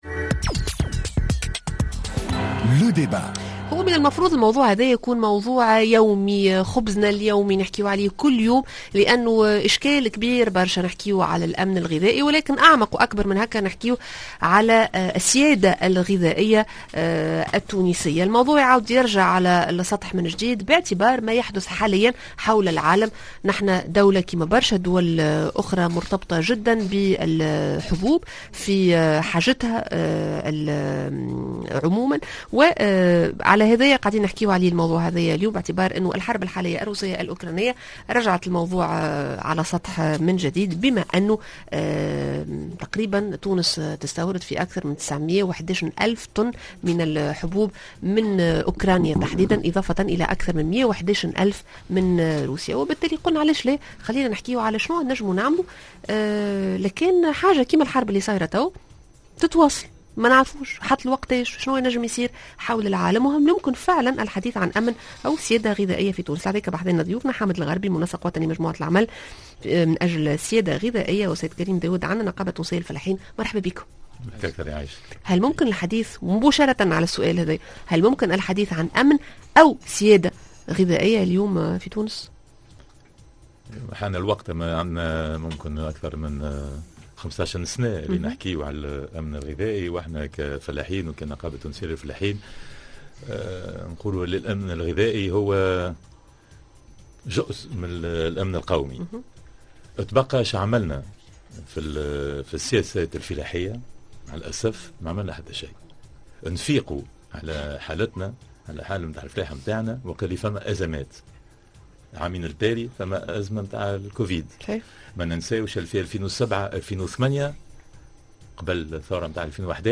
Le débat: هل أمننا الغذائي مهدد؟